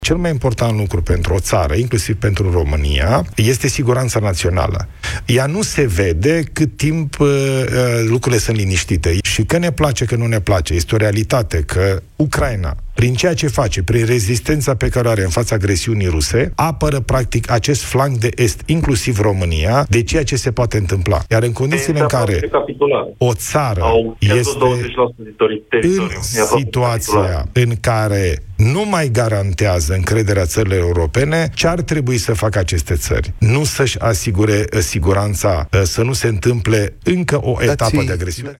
Premierul Ilie Bolojan: „Că ne place, că nu ne place, este o realitate că Ucraina, prin rezistența pe care o are în fața agresiunii ruse, apără acest flanc de Est, inclusiv România”